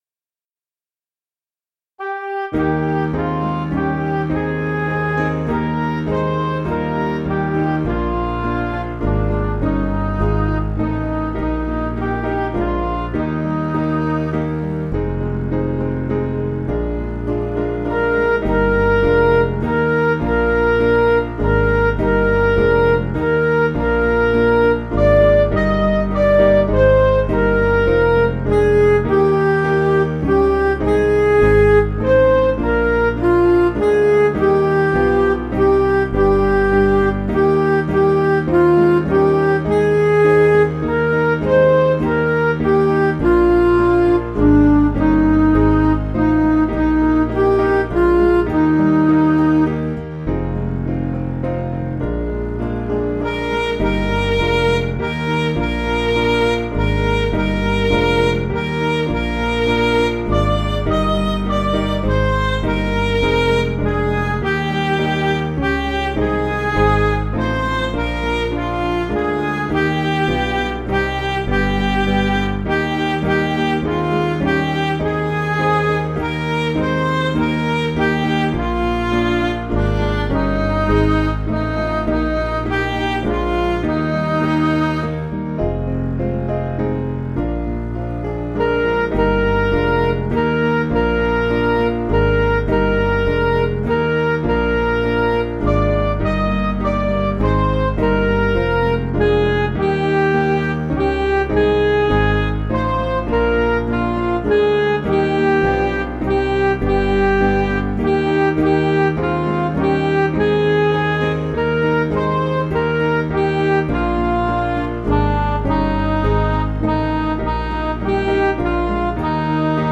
Small Band
(CM)   5/Eb 566.2kb